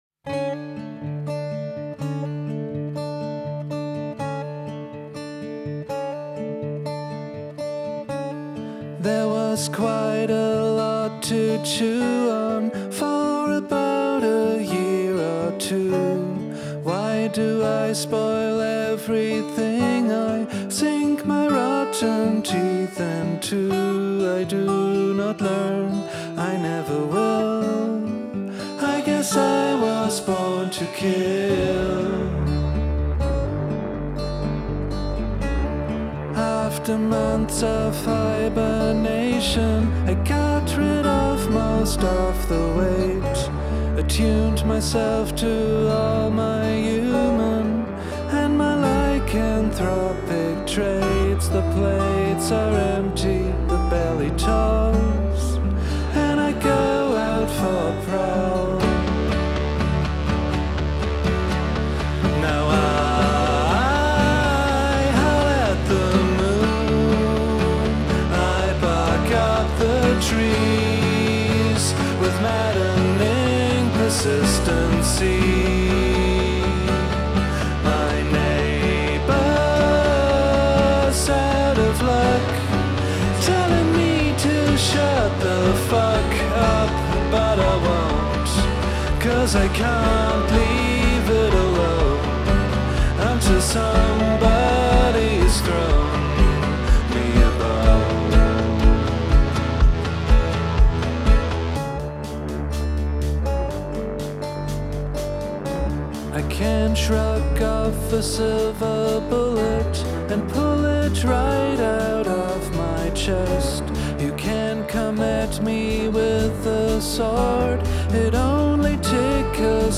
Bass Synth könnte tatsächlich mehr Sub Energie brauchen, damit die Hose flattert. Drums mehr up front geht auch, aber für mich könnte man sie - je nach neuem Kontext mit Bass Synth - auch ruhig so lo-fi lassen.